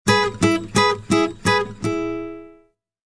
Descarga de Sonidos mp3 Gratis: guitarra a 1.